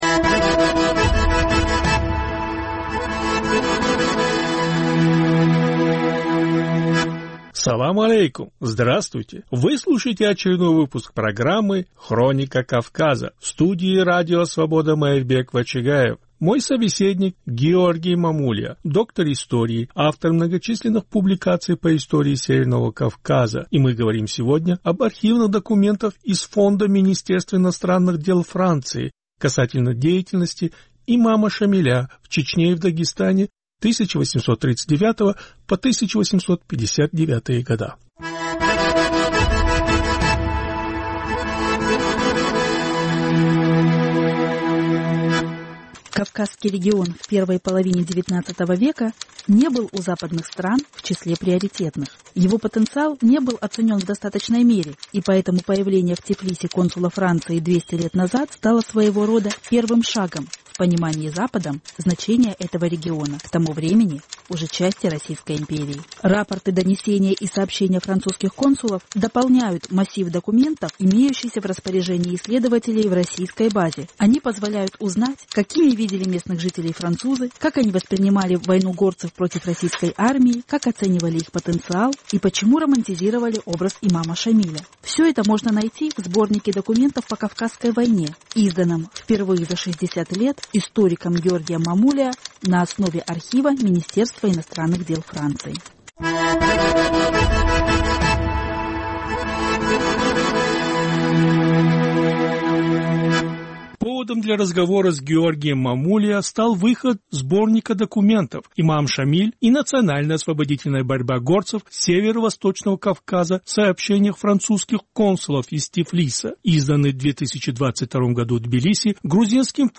Повтор эфира от 22 мая 2022 года.